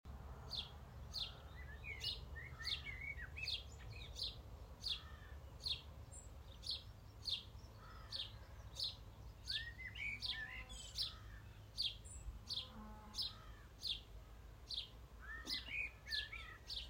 Rural Soundscape
Sounds around me.